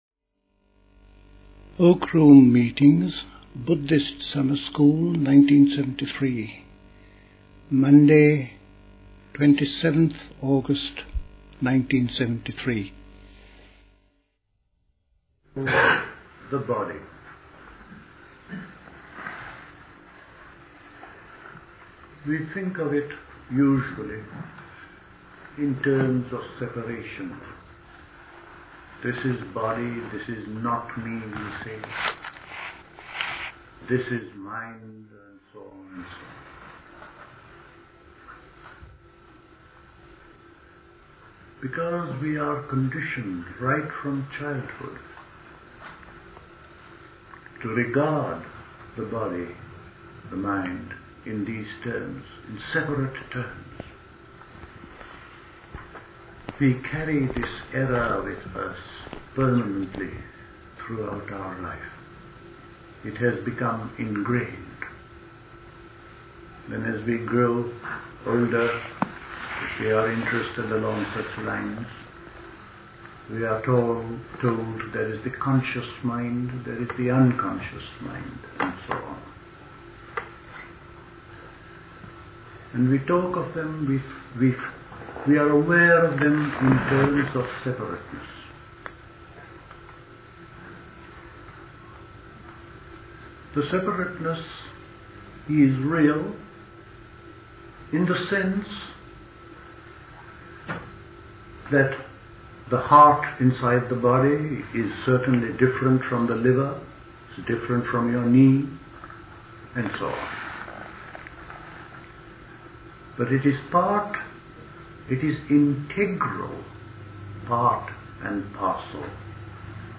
Recorded at the 1973 Buddhist Summer School.